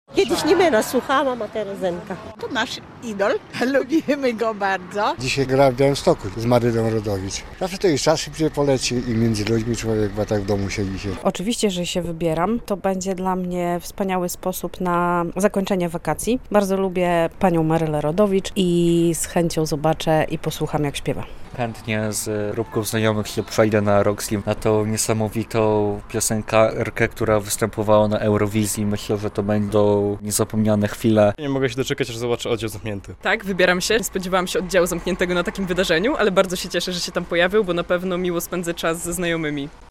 "Białystok pełen muzyki". Mieszkańcy nie mogą już doczekać się koncertów - relacja